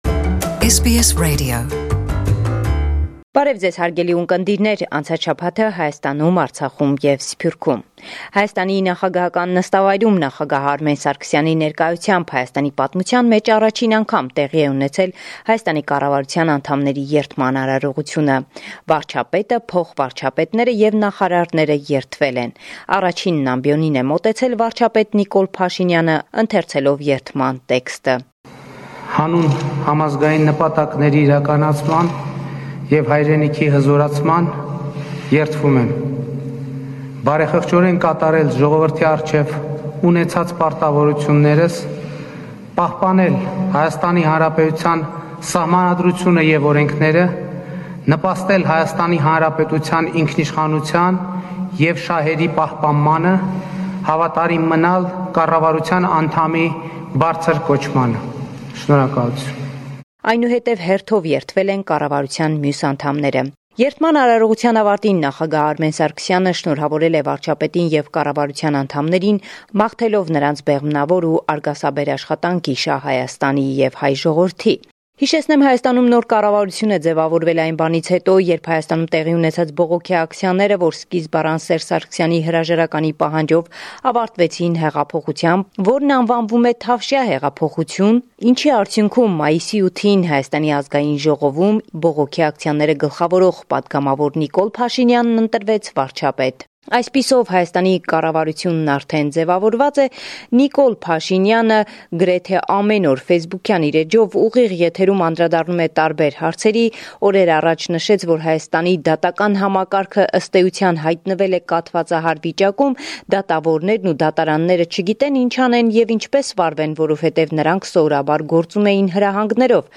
Վերջին Լուրերը – 22 Մայիս 2018